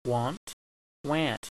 • the nasal a (pronounced like the "ah" in apple)